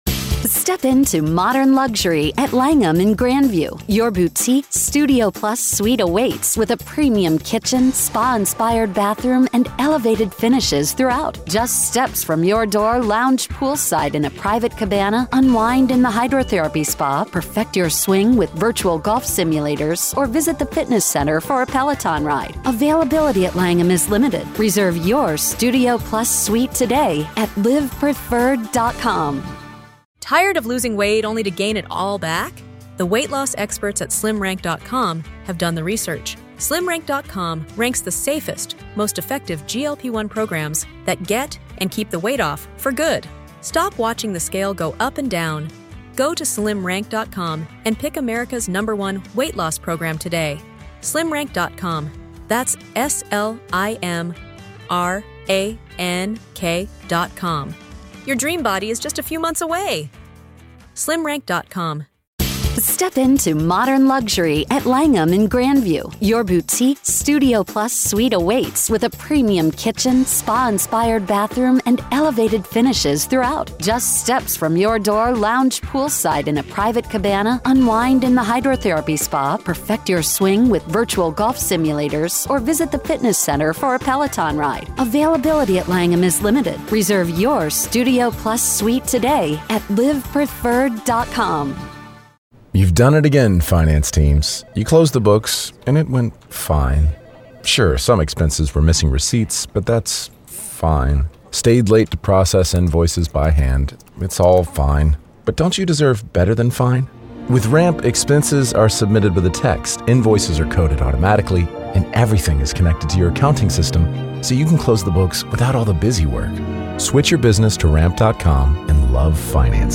The conversation provided insights into the legal intricacies and potential outcomes of the ongoing trial, underscoring the importance of proper legal procedures and the challenges faced by both the defense and prosecution.